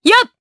Seria-Vox_Attack2_jp.wav